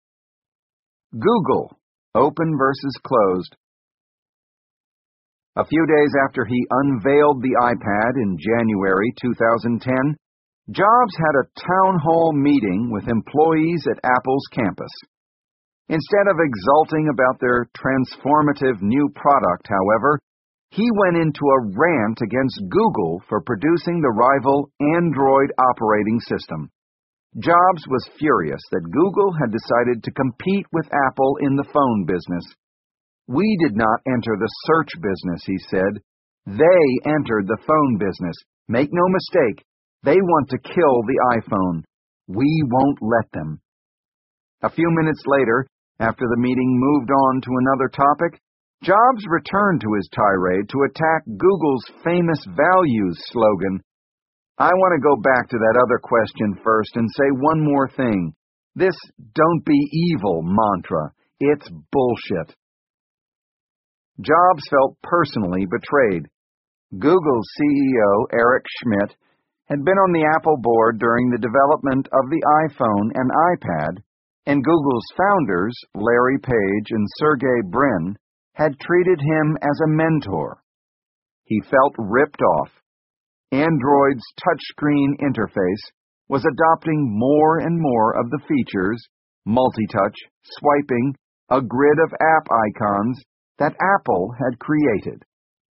在线英语听力室乔布斯传 第710期:谷歌 开放与封闭(1)的听力文件下载,《乔布斯传》双语有声读物栏目，通过英语音频MP3和中英双语字幕，来帮助英语学习者提高英语听说能力。
本栏目纯正的英语发音，以及完整的传记内容，详细描述了乔布斯的一生，是学习英语的必备材料。